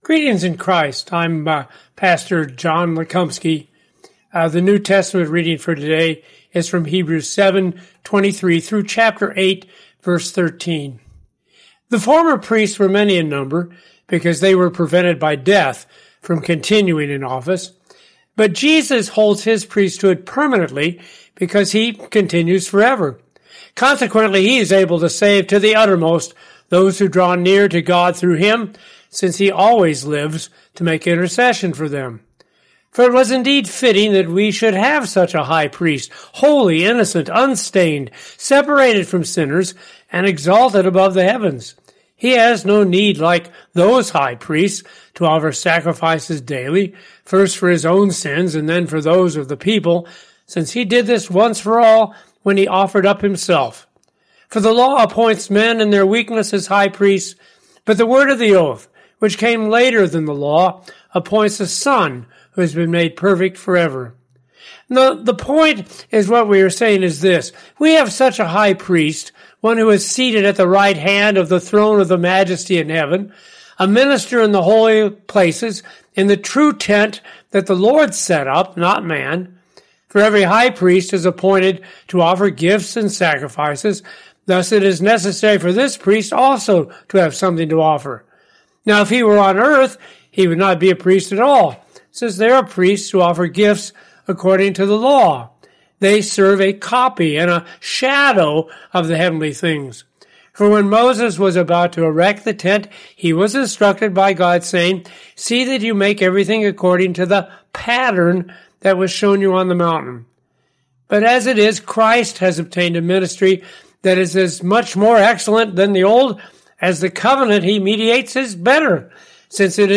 Morning Prayer Sermonette: Hebrews 7:23-8:13